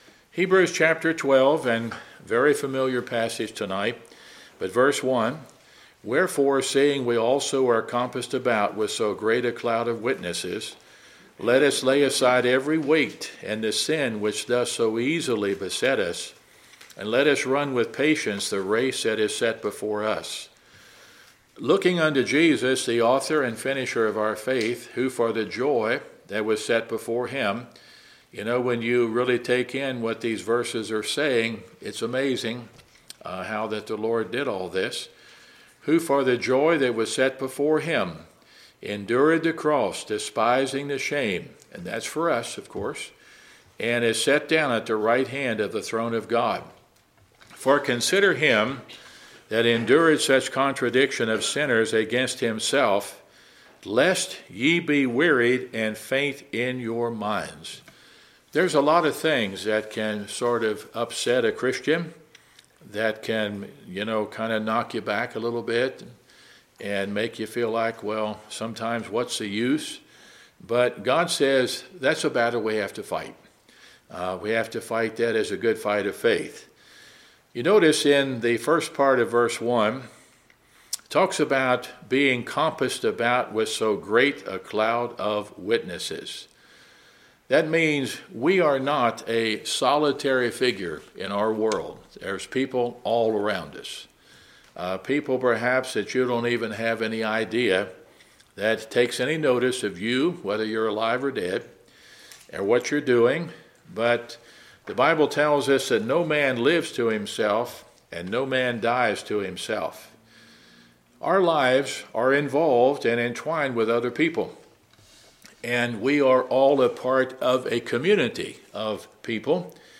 2024 Series: Wednesday Sermon Book